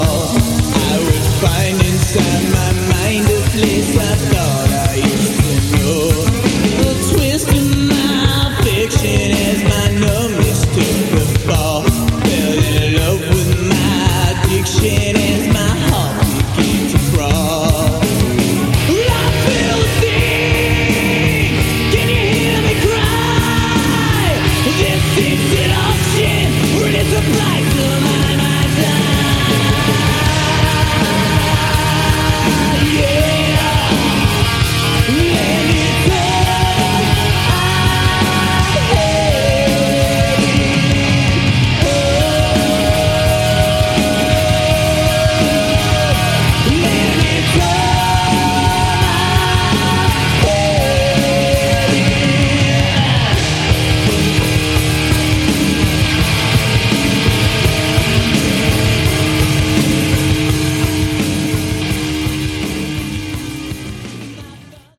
Category: Hard Rock
lead guitar, backing vocals
bass
drums, percussion